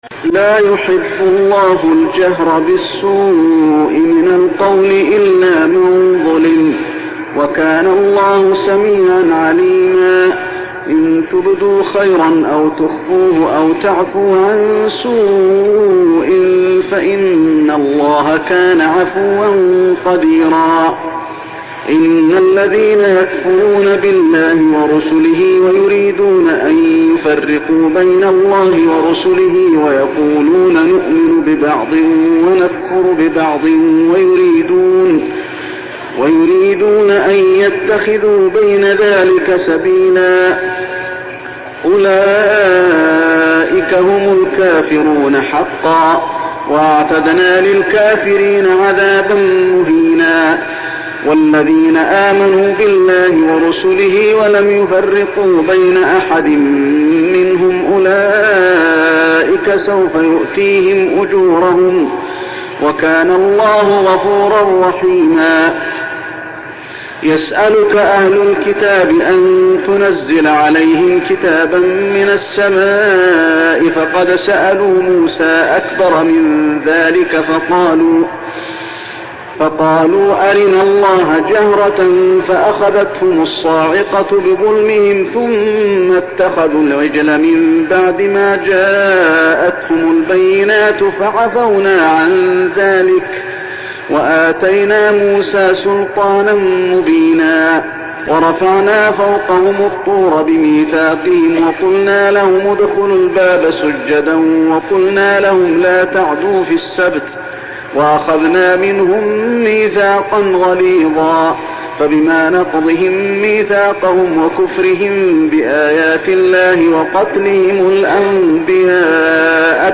صلاة التراويح ليلة 6-9-1409هـ سورتي النساء 148-176 و المائدة 1-26 | Tarawih prayer Surah An-Nisa and Al-Ma'idah > تراويح الحرم المكي عام 1409 🕋 > التراويح - تلاوات الحرمين